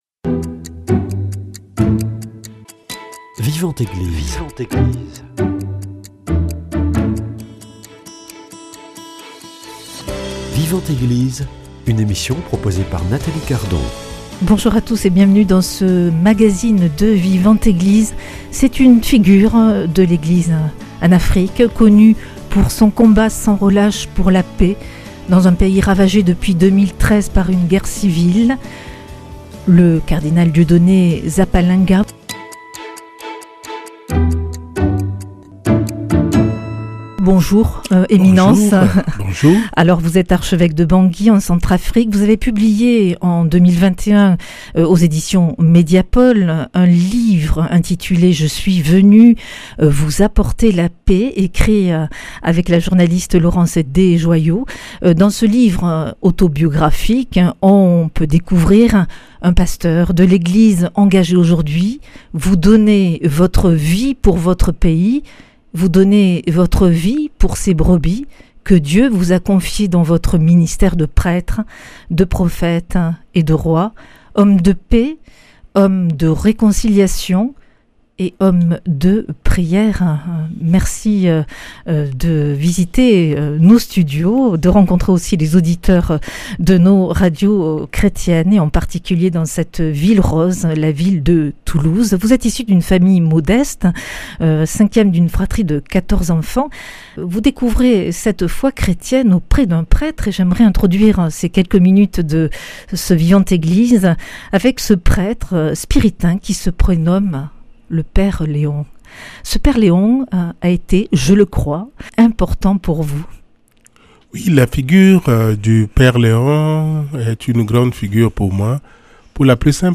Invité : Cardinal Dieudonné Nzapalainga, archevêque de Bangui.